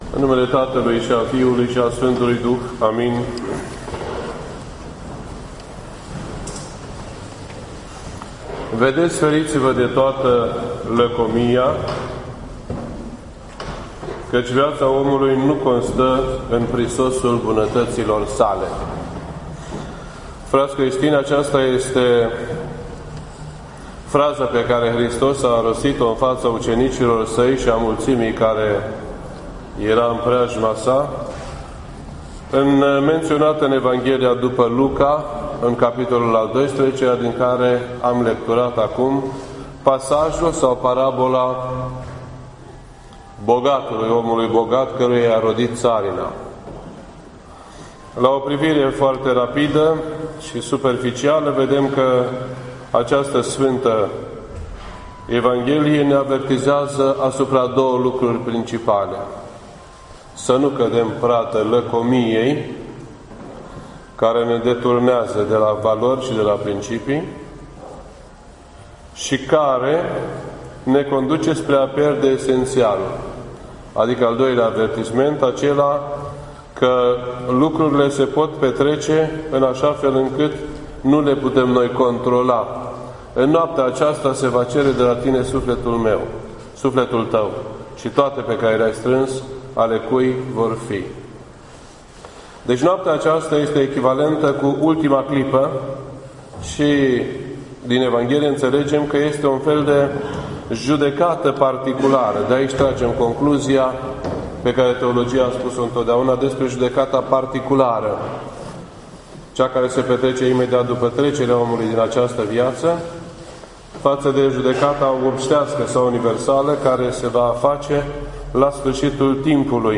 This entry was posted on Sunday, November 20th, 2016 at 10:24 AM and is filed under Predici ortodoxe in format audio.